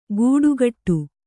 ♪ gūḍugaṭṭu